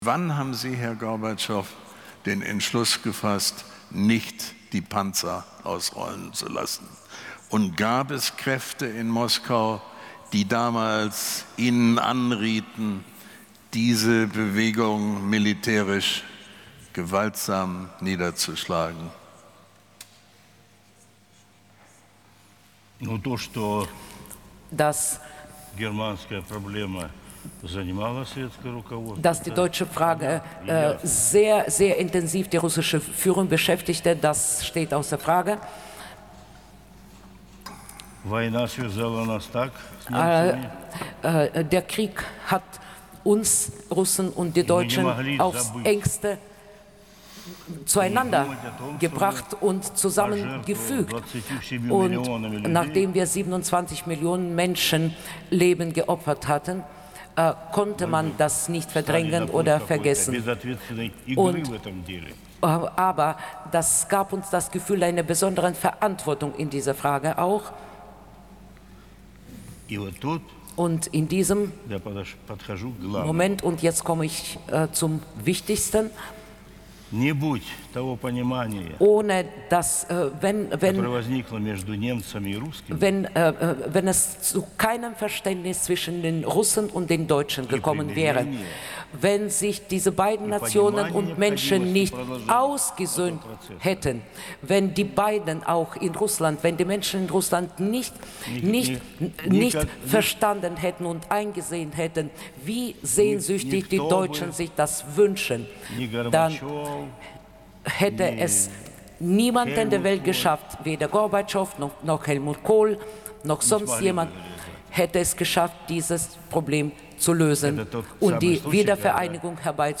(ca. 4 min, auf mp3 komprimiert, Übersetzung mitaufgezeichnet) zur Frage, warum die sowjetischen Truppen 1989 in den Kasernen in der DDR blieben: